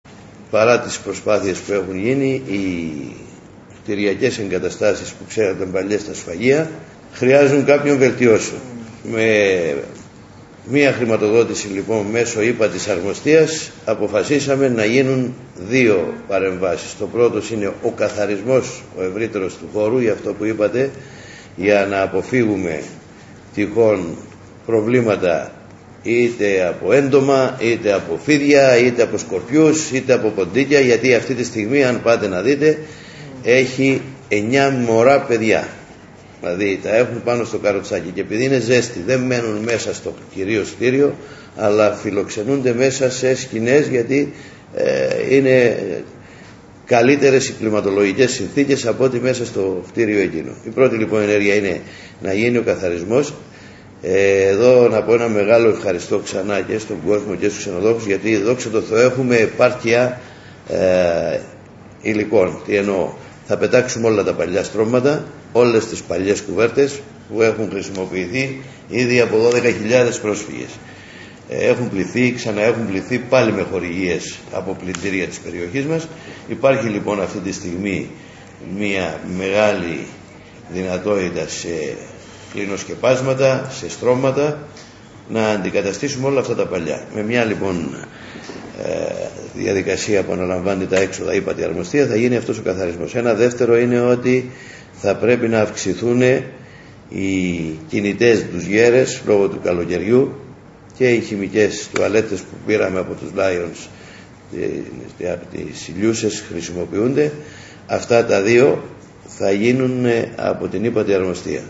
Ο Δήμαρχος Ρόδου Φώτης Χατζηδιάκος, δήλωσε…[Ηχητικό]